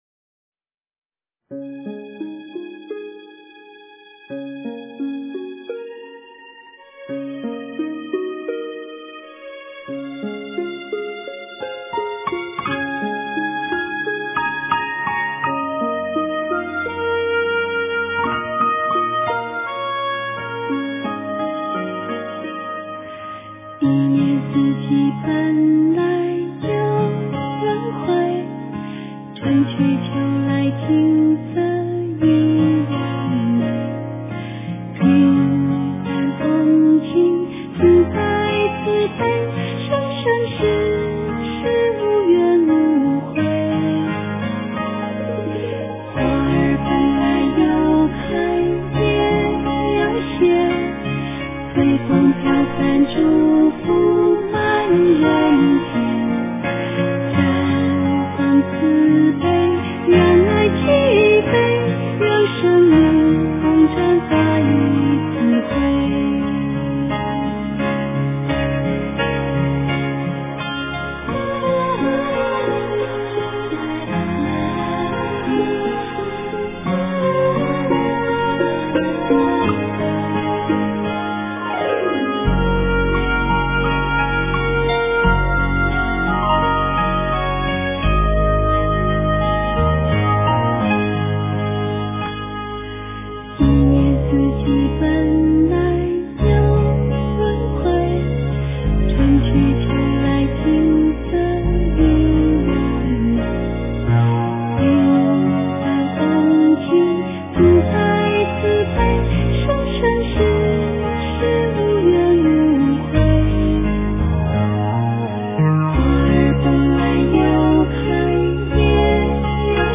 佛音 诵经 佛教音乐 返回列表 上一篇： 观音灵感真言(梦授咒